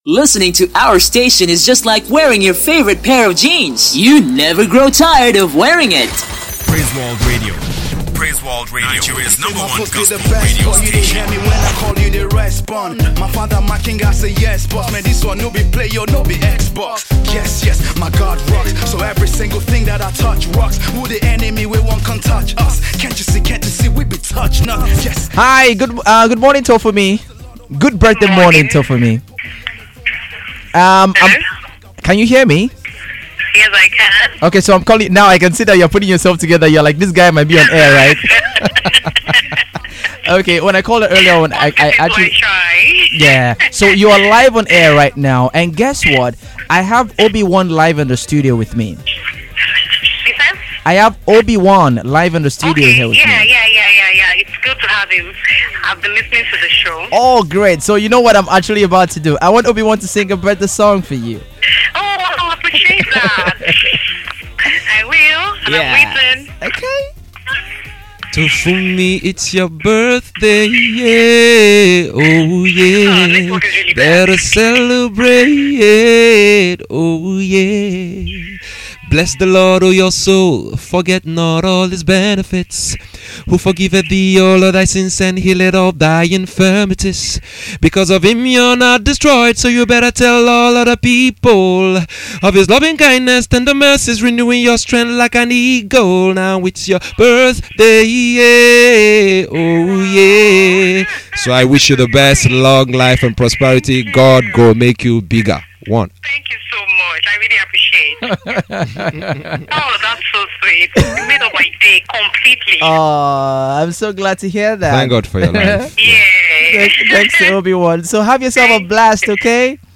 Coincidentally, Nigerian popular artiste turned gospel minister Obiwon happened to be the guest on the show. He made the celebrant’s day by singing a special birthday song for her.